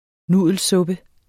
Udtale [ ˈnuðˀəlˌsɔbə ]